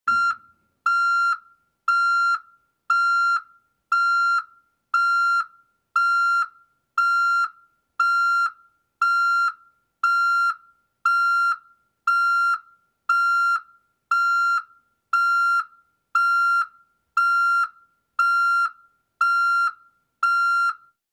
Звуки грузовика, фуры